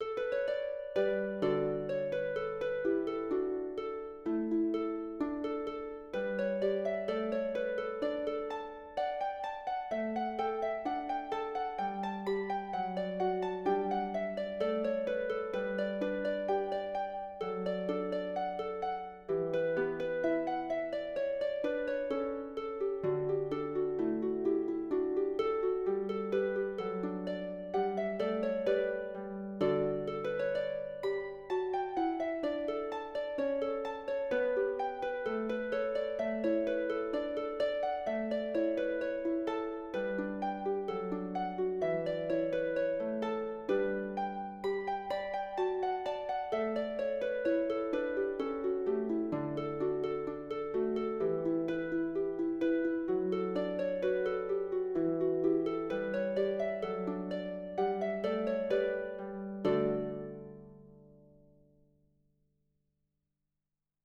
for the double-strung lever harp